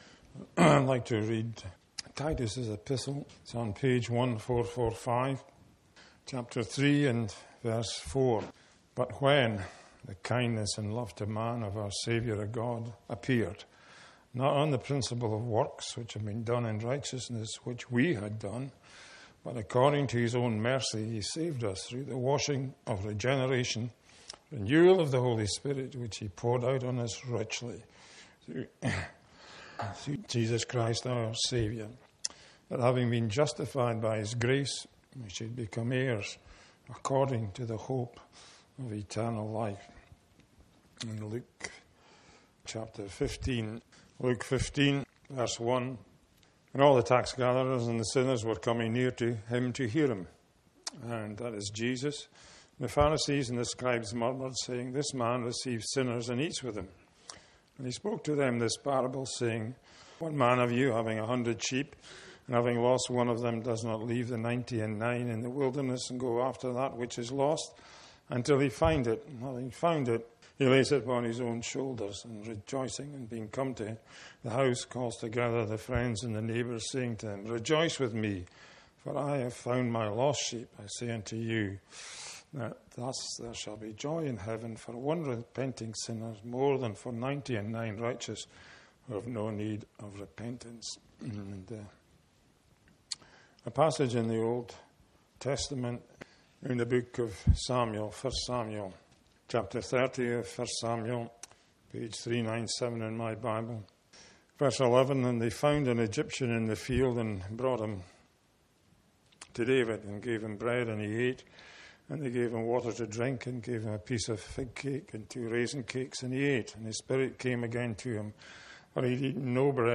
That is the searching question at the heart of this gospel preaching. It speaks to the reality that we are not our own—that every soul belongs either to sin or to the Saviour who gave Himself at the cross. With clarity and urgency, the message unfolds God’s kindness and mercy, showing that salvation is not earned, but given through Jesus Christ, who came to seek and to save the lost.